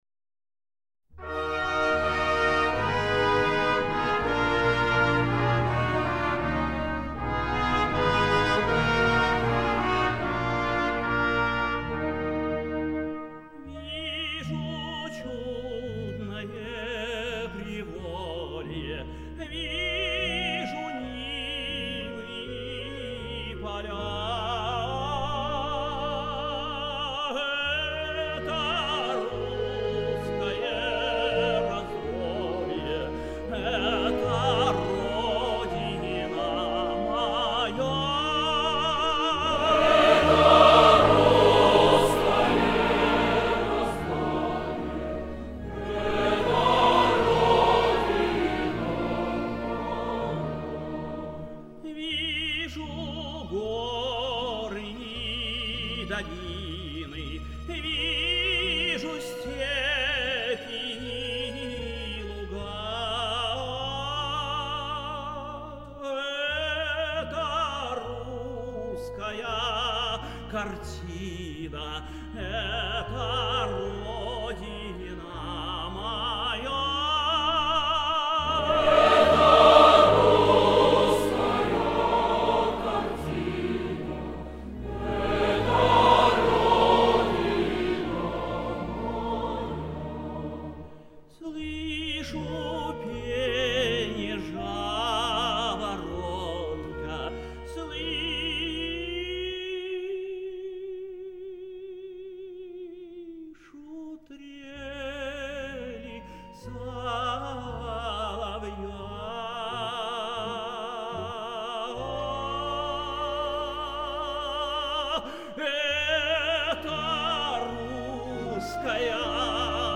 Красивые записи со смешанным хором.